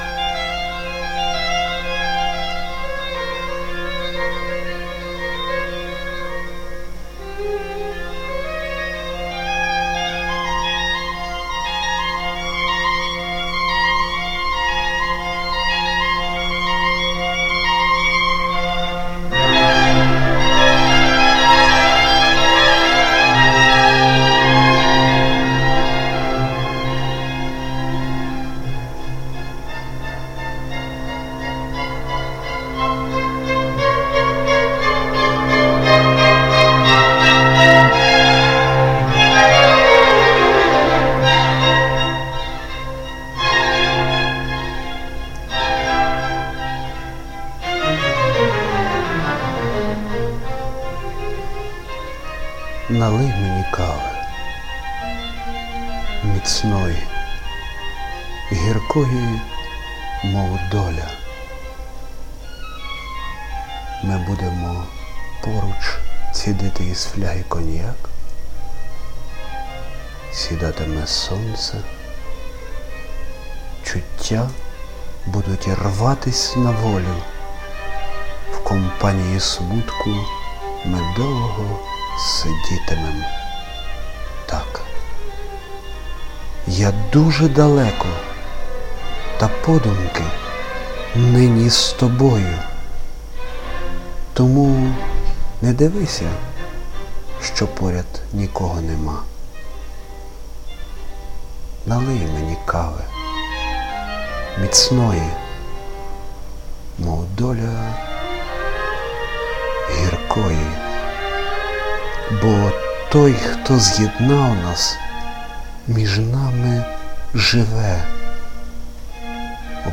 Чудова гармонія слів, музики і голосу.